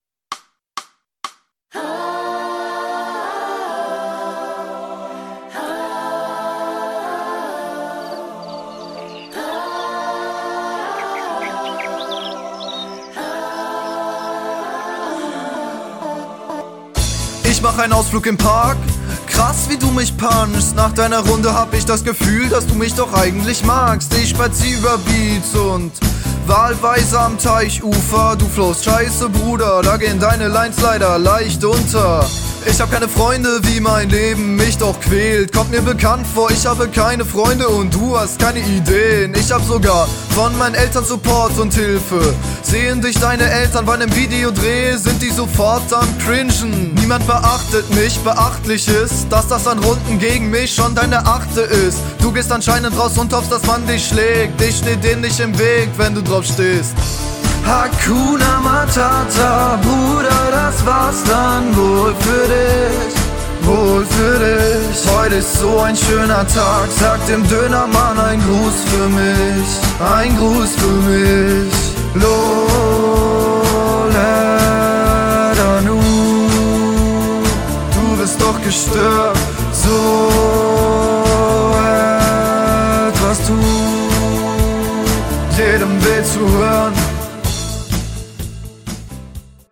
Flowtechnisch weniger cool, aber der Stimmeinsatz ist etwas besser.